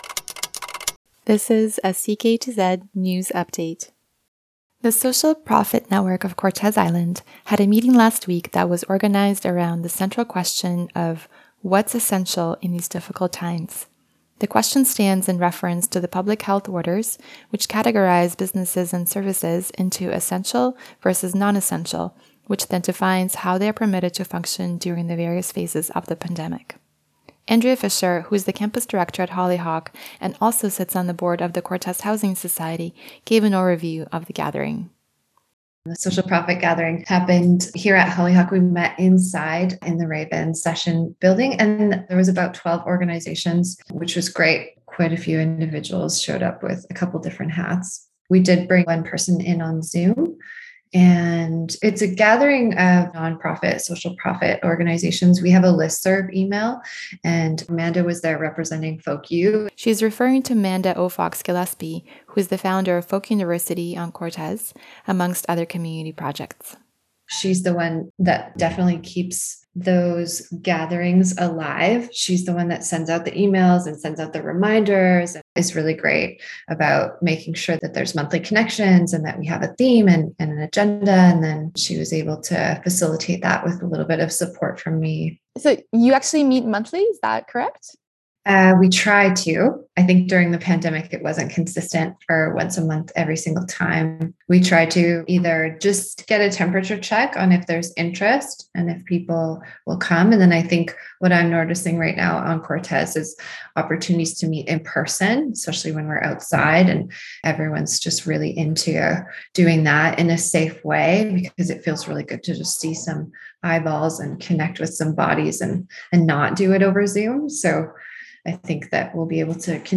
CKTZ-News-20-oct-21-Social-Profit-Network-meeting.mp3